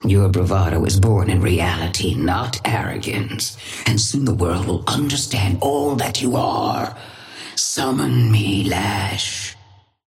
Sapphire Flame voice line - Your bravado is born in reality, not arrogance, and soon the world will understand all that you are.
Patron_female_ally_lash_start_01_alt_01.mp3